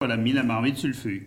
Sallertaine ( Plus d'informations sur Wikipedia ) Vendée
Locutions vernaculaires